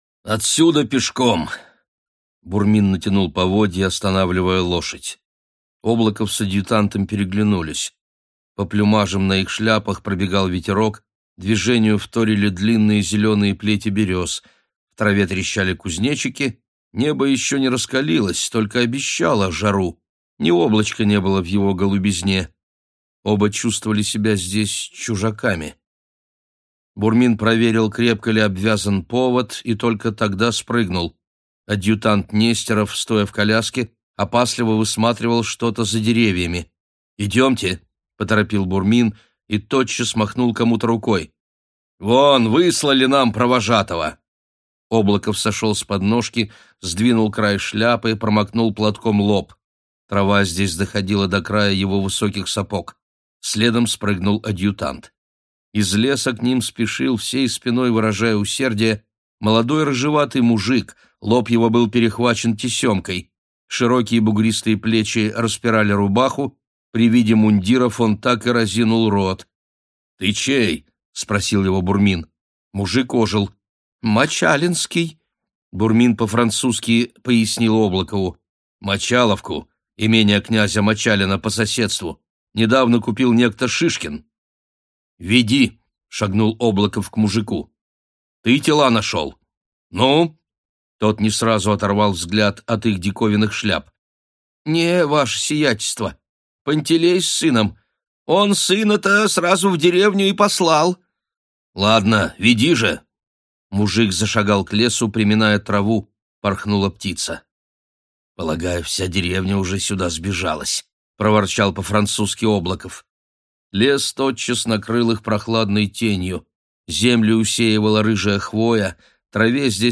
Аудиокнига Нашествие | Библиотека аудиокниг